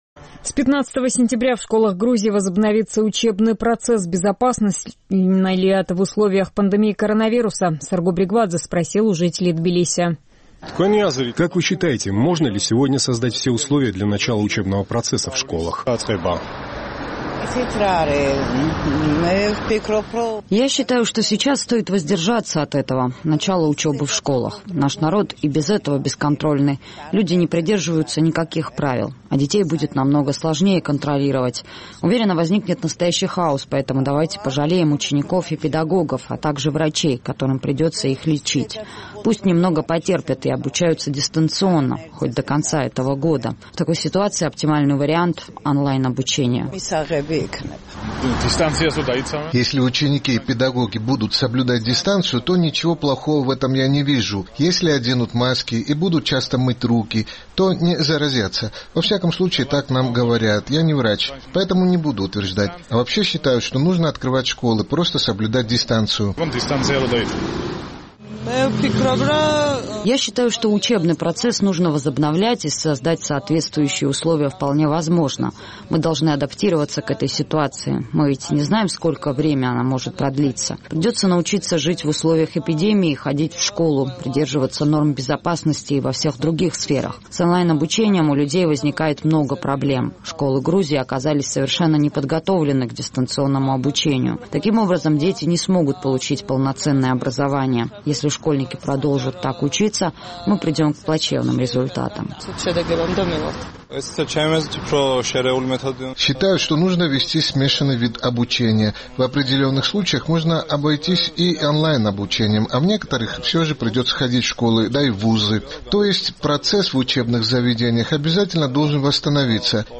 Своим мнением на этот счет поделились жители Тбилиси.